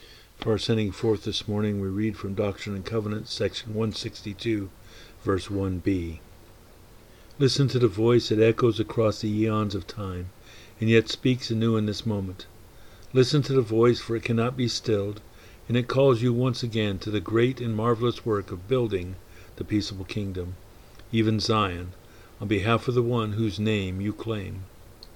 January 22 2023 Service